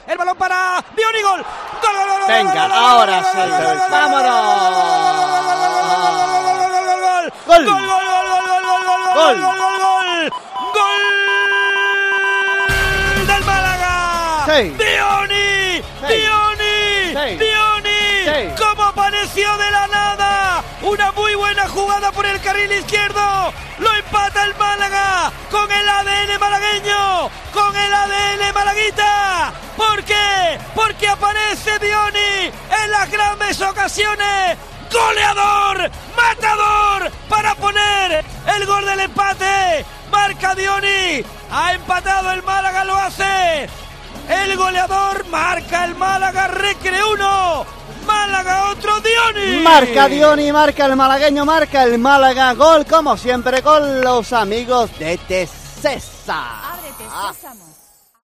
Así te hemos narrado el gol del Málaga en Huelva (1-1)